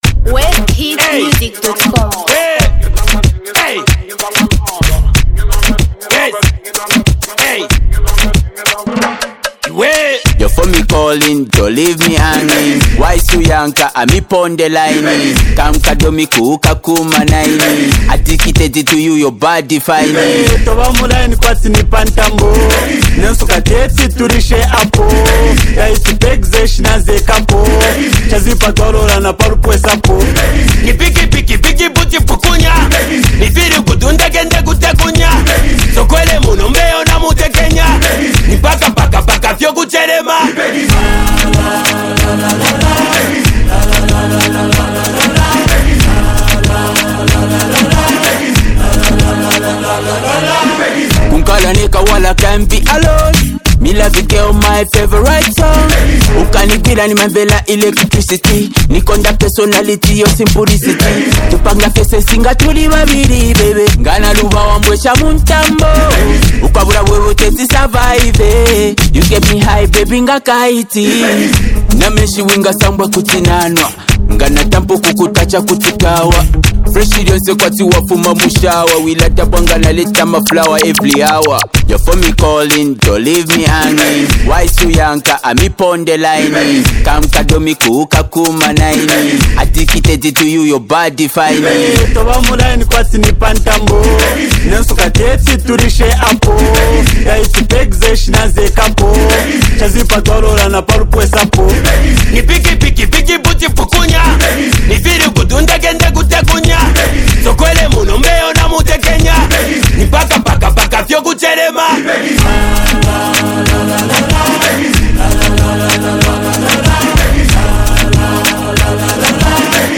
Zambia Music
dance track